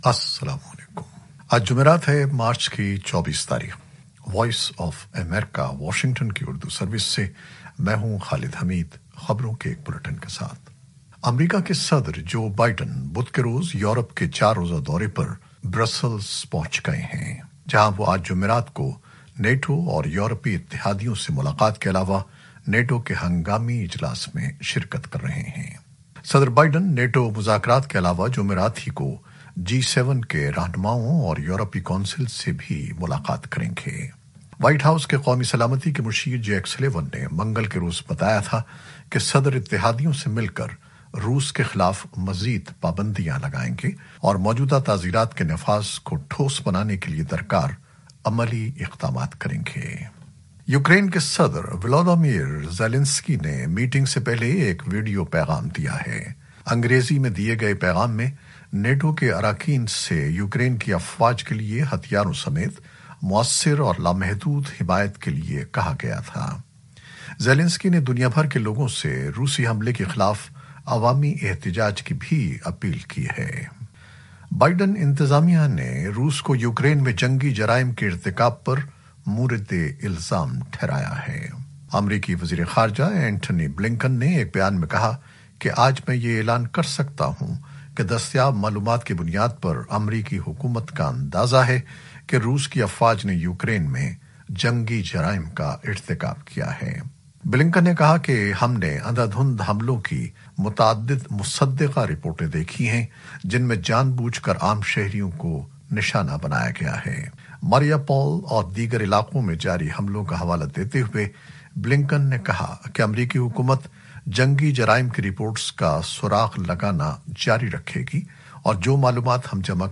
نیوز بلیٹن 2021-24-03